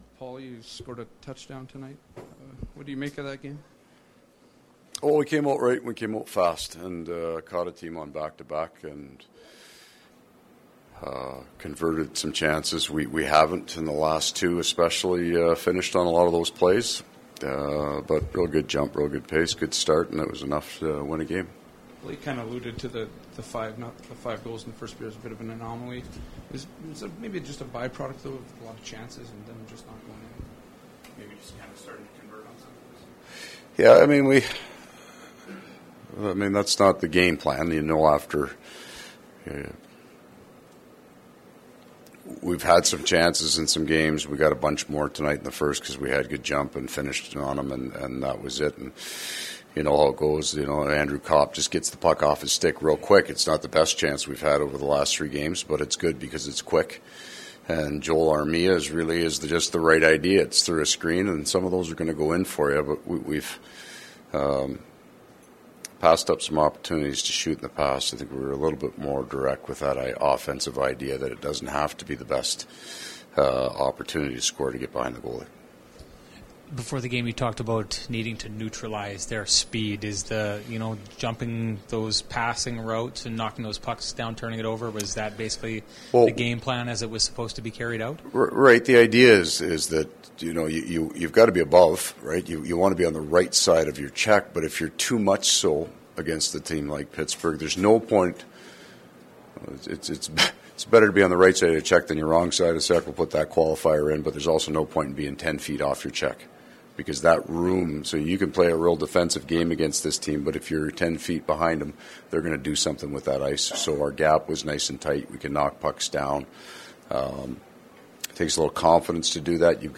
Post-game from the Jets dressing room as well as from Coach Maurice.
Jets post-game audio: